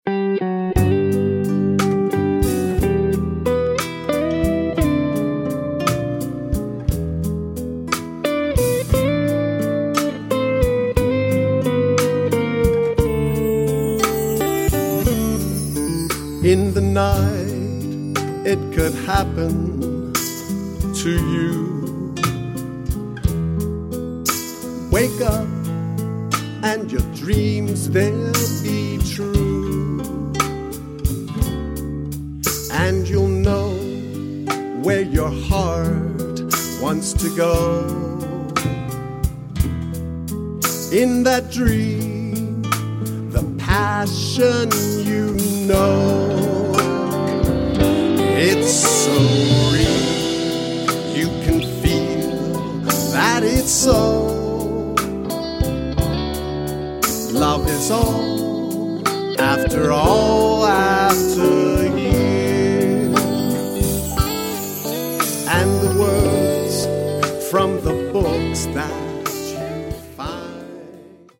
A laid back wholesome piece of jazz pop. Vocal version.